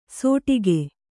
♪ sōṭige